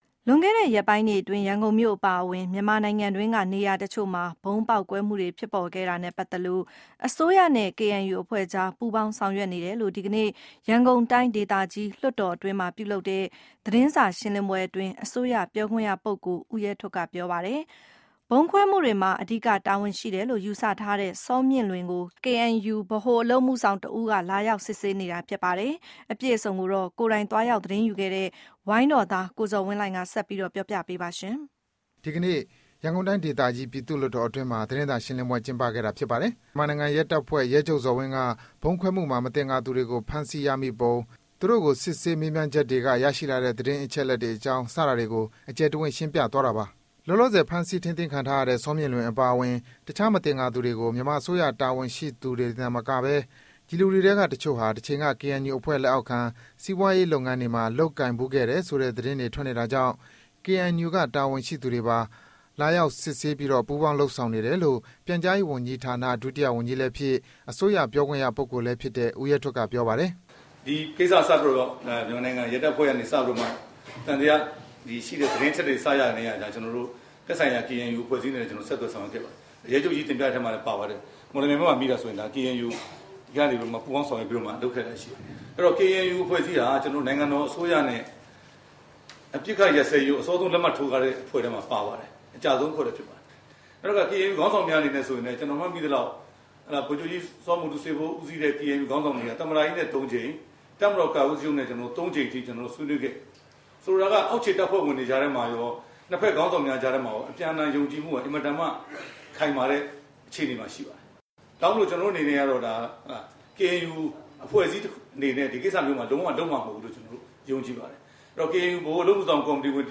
ဗုံး သတင်းစာရှင်းလင်းပွဲ